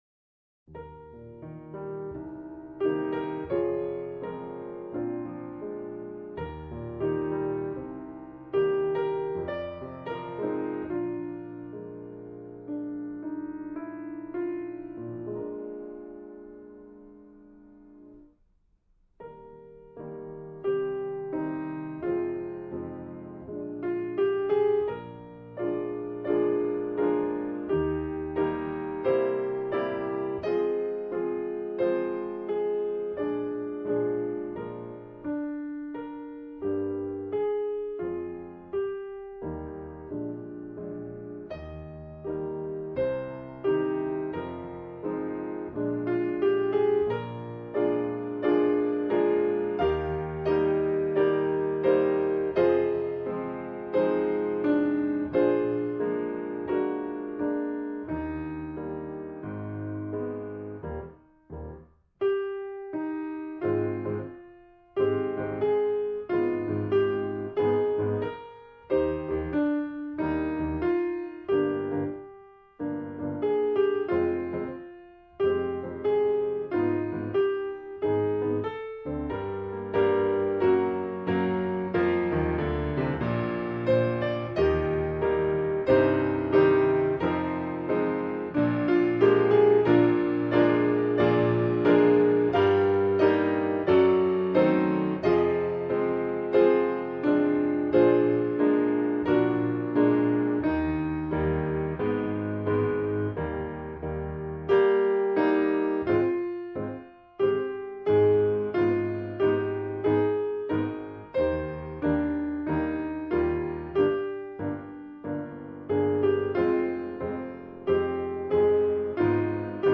เพลงพระราชนิพนธ์